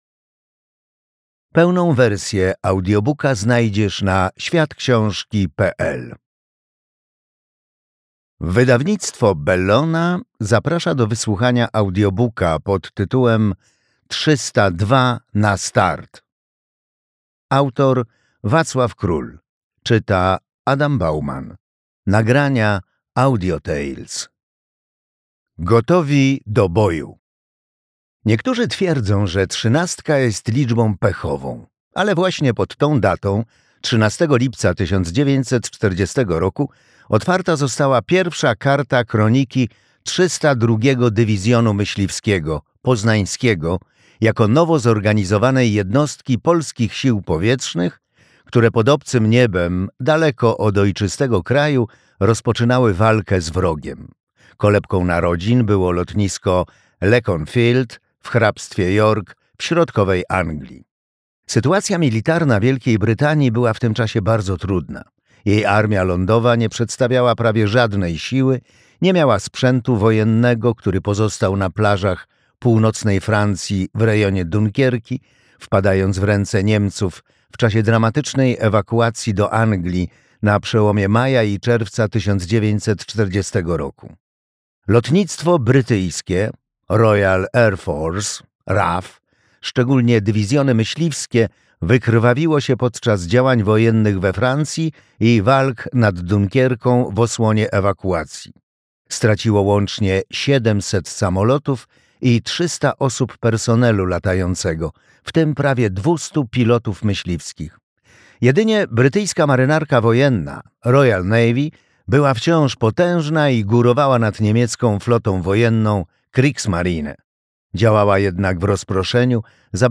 302 na start - Wacław Król - audiobook